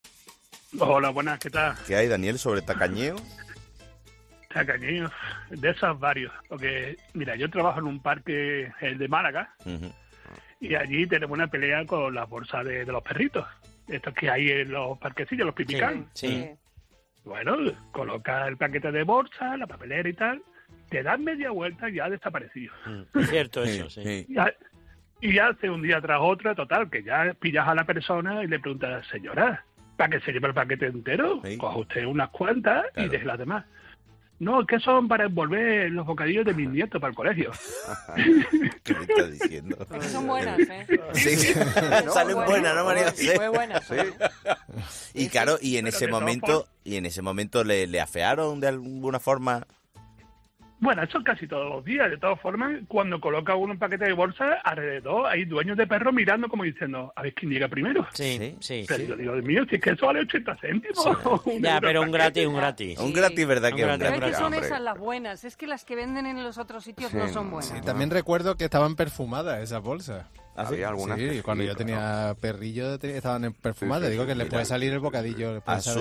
Oyente de COPE